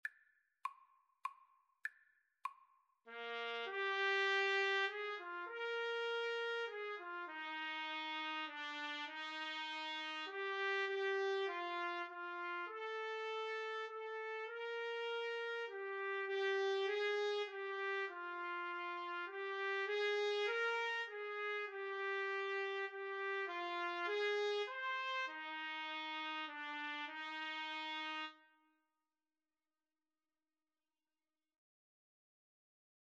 3/4 (View more 3/4 Music)
Trumpet Duet  (View more Easy Trumpet Duet Music)
Classical (View more Classical Trumpet Duet Music)